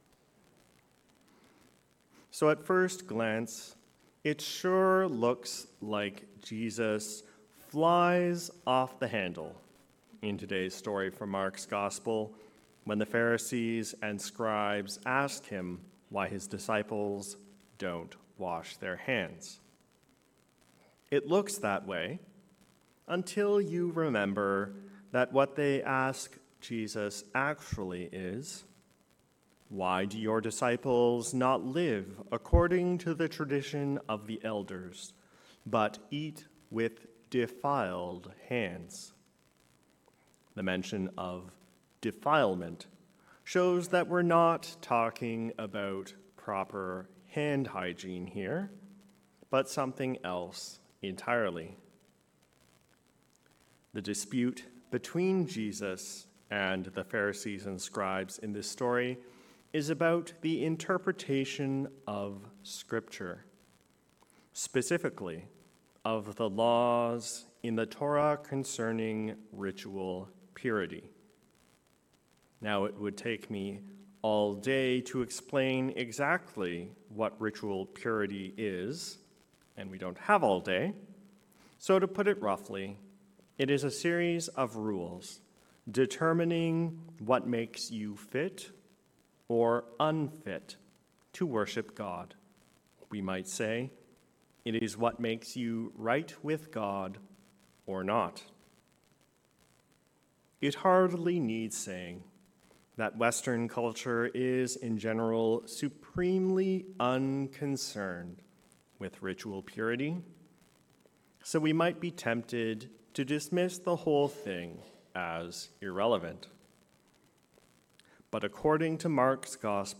A sermon on Mark 7:1-8, 14-15, 21-23